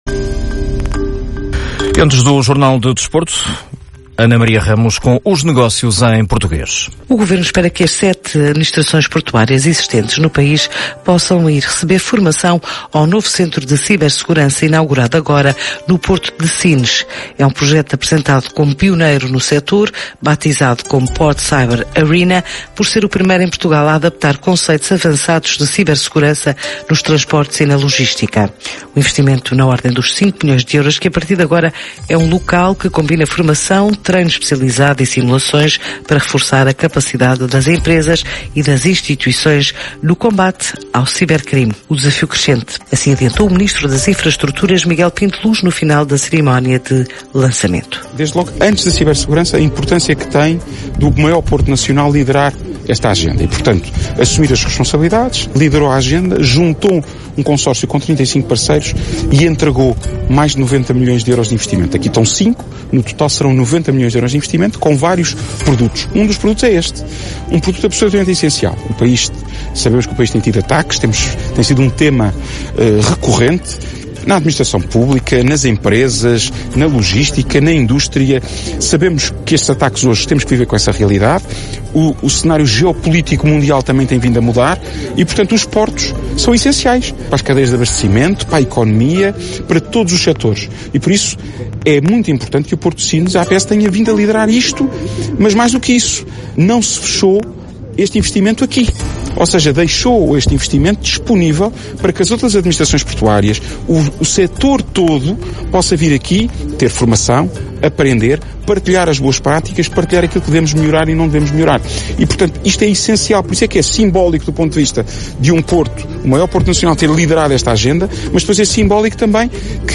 Declarações do ministro Miguel Pinto Luz para ouvir aqui